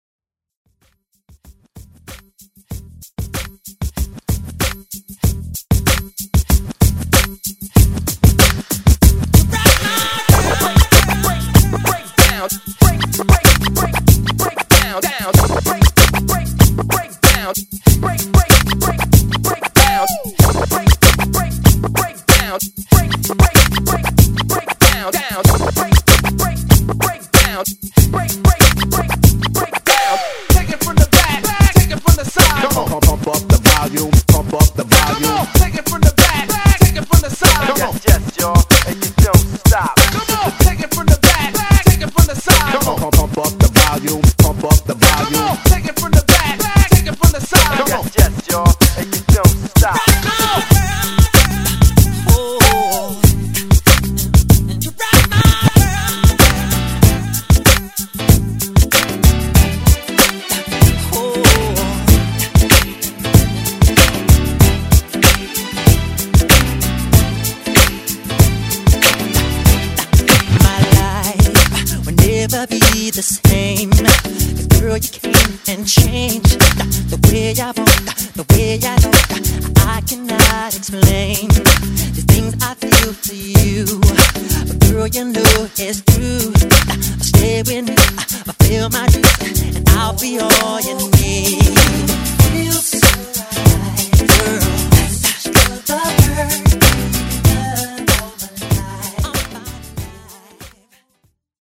Genre: 2000's Version: Clean BPM: 95 Time